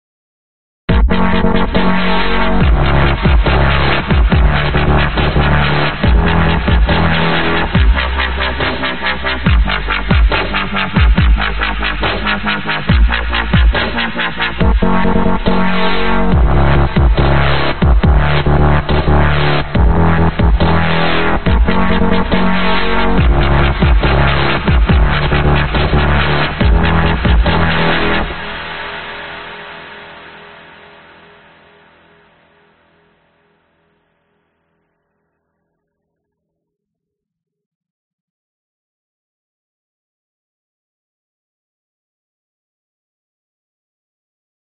摇摆不定的巴斯10
描述：湿滑的合成器和摇摆不定的低音/合成器，有鼓声和无鼓声；还包括一个干的版本和一个湿的版本的低音和一个鼓点的茎。
Tag: 低音 节拍 dubstep 合成器 摇摆